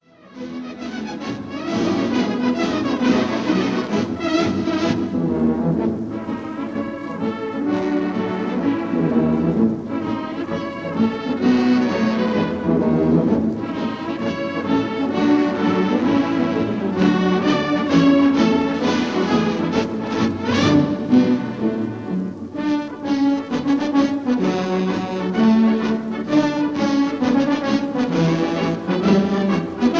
Recorded live in the Crystal Palace, London